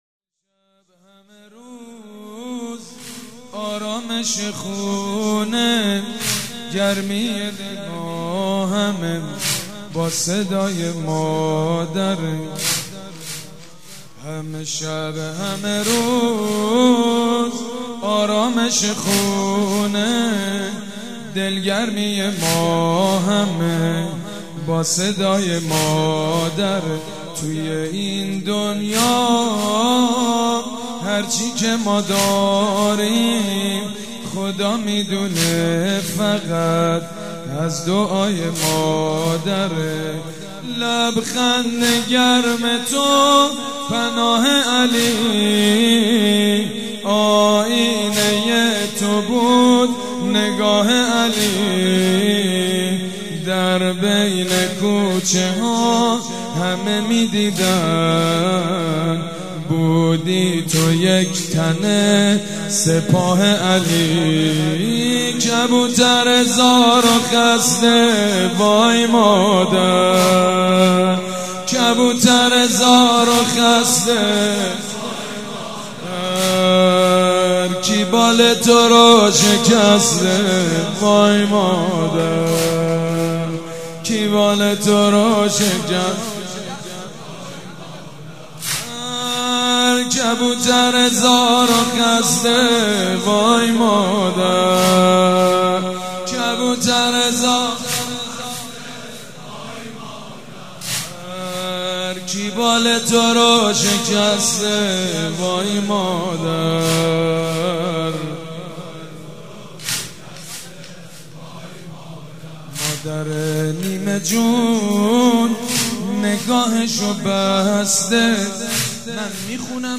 شب پنجم فاطميه دوم١٣٩٤
مداح
مراسم عزاداری شب شهادت حضرت زهرا (س)